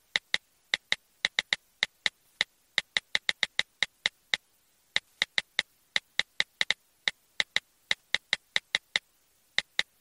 Play Tastiera Cellulare - SoundBoardGuy
tastiera-cellulare-1.mp3